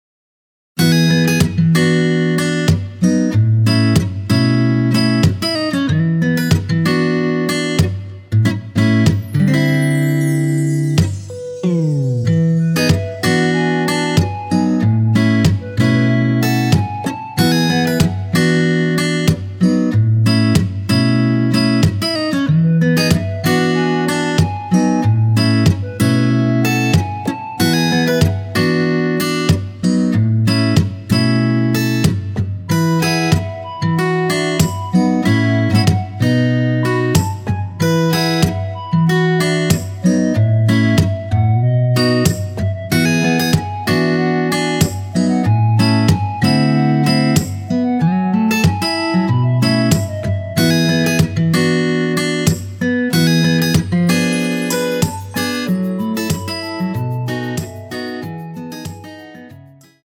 원키에서(+3)올린 멜로디 포함된 MR입니다.
멜로디 MR이란
앞부분30초, 뒷부분30초씩 편집해서 올려 드리고 있습니다.
중간에 음이 끈어지고 다시 나오는 이유는